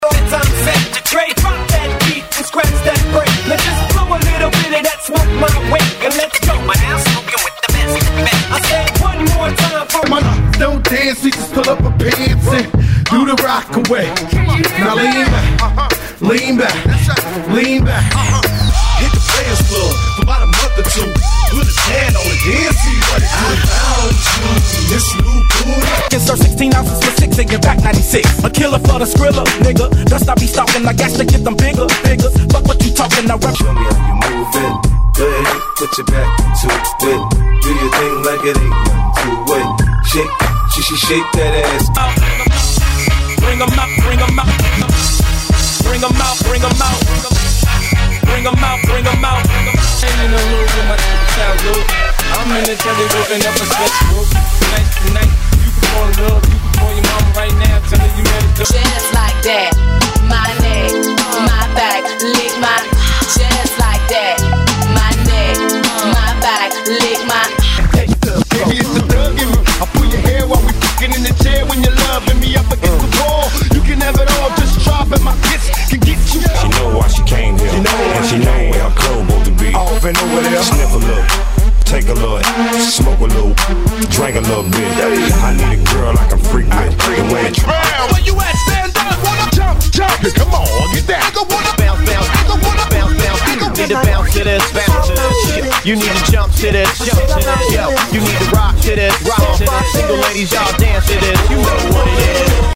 Genre: HIPHOP
Dirty BPM: 95 Time